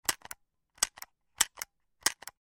Звуки пульта от телевизора
Нажимаем кнопки на пульте от телевизора